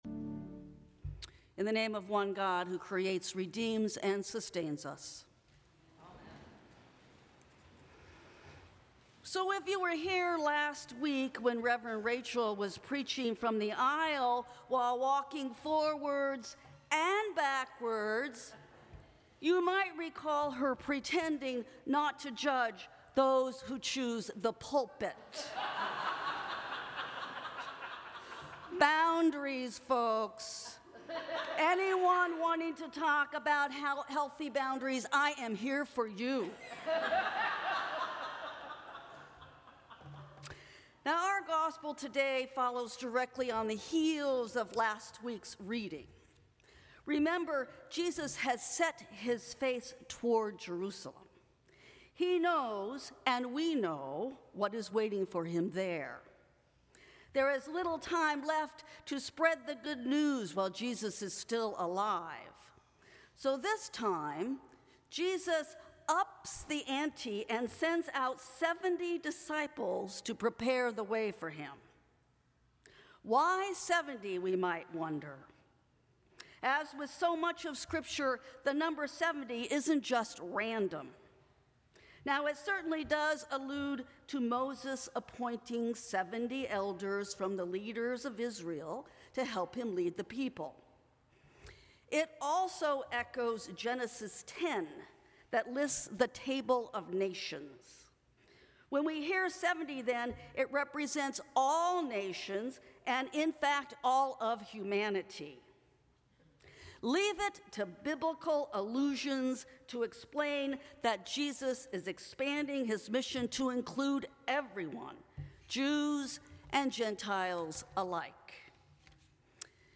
Sermons from St. Cross Episcopal Church Fourth Sunday after Pentecost Jul 06 2025 | 00:10:19 1x 00:00 / 00:10:19 Subscribe Share Apple Podcasts Spotify Overcast RSS Feed Share Link Embed